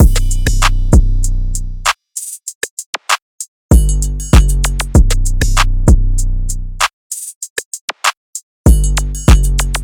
Groove (194 BPM – Bm)